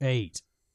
Voices / Male / 8.wav